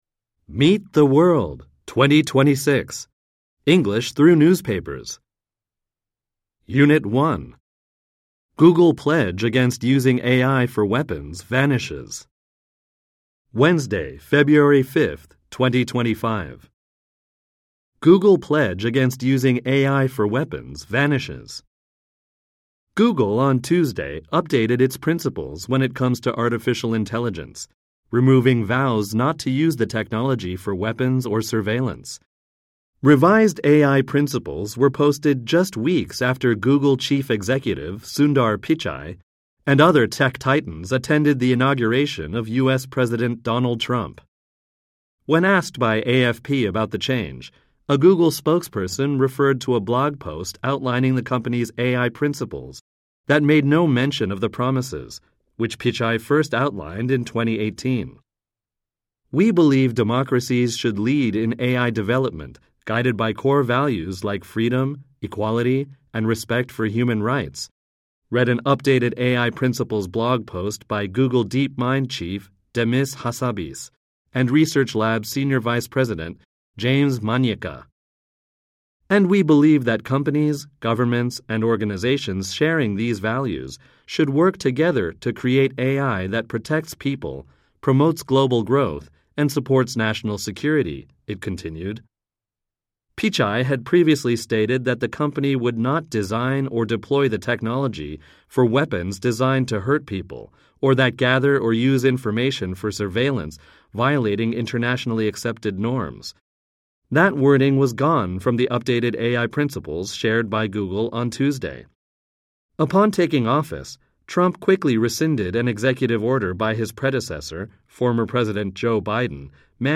Audio Language Amer E